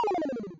checkmate.mp3